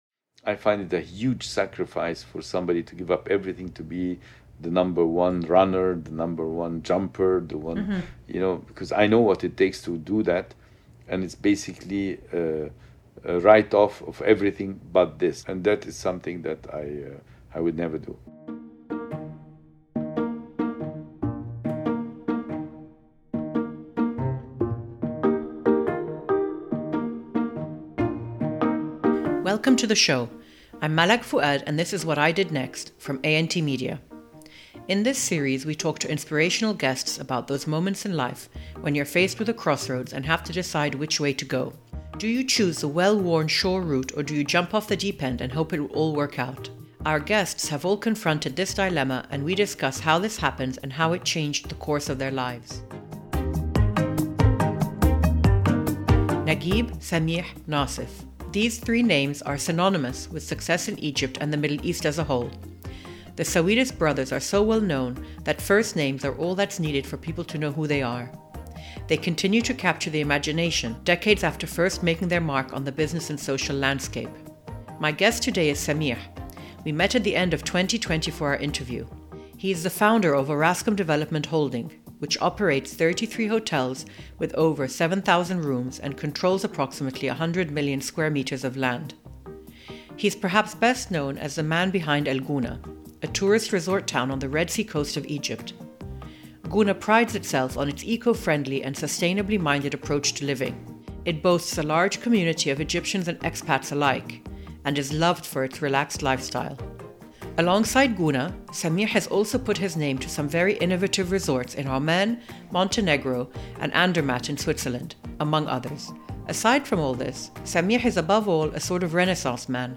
Our interview was recorded at the end of 2020.